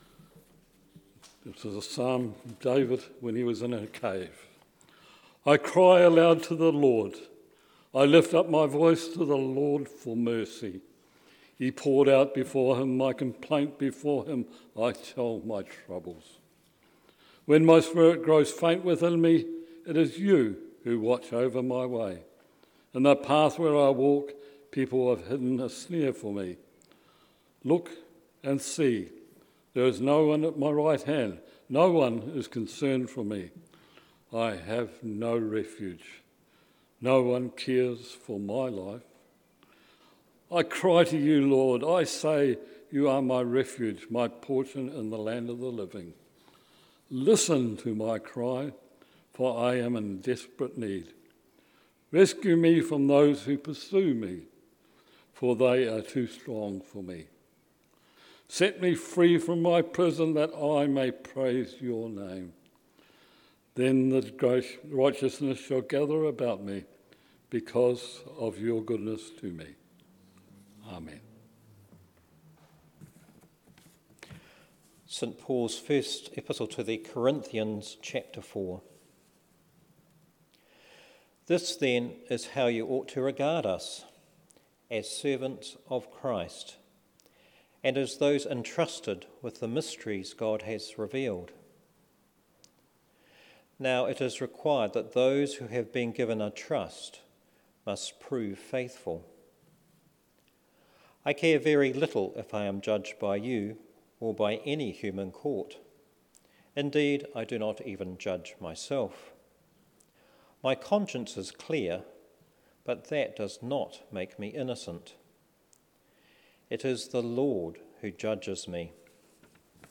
A homily for Evensong;